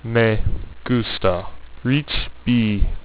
This is because of the dead space that is inherent at the start and end of file, due to the delay between recording beginning and the speech sample starting (and similarly at the end).